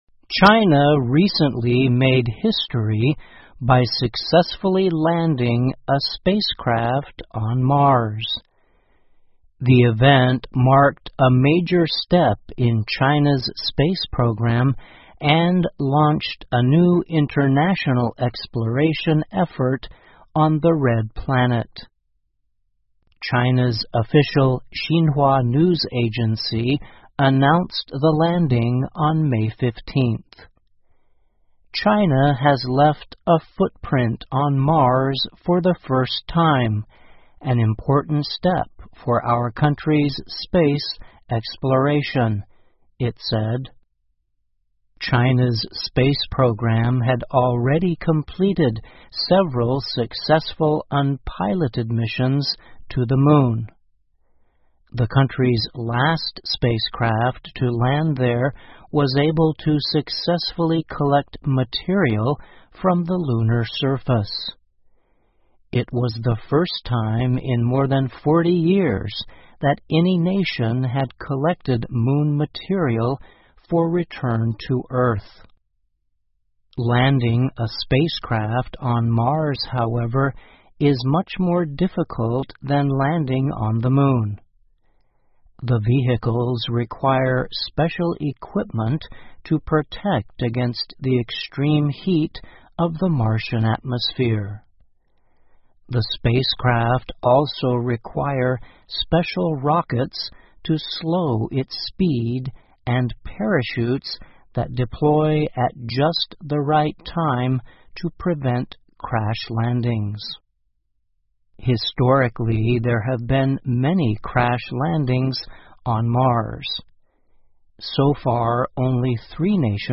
VOA慢速英语--既然中国已经登陆火星了,接下来的任务是什么? 听力文件下载—在线英语听力室